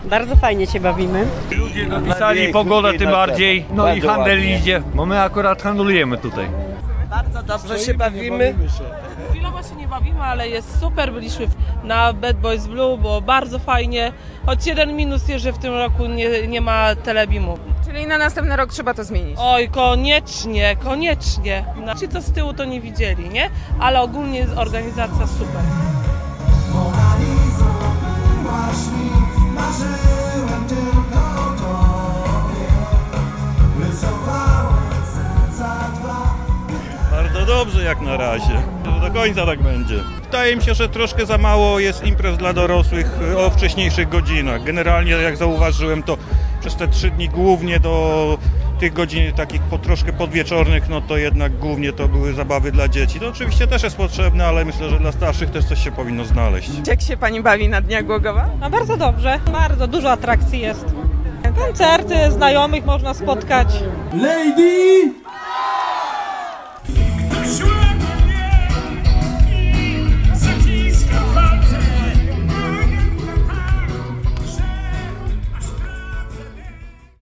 0606_sonda_dni_glogowa.mp3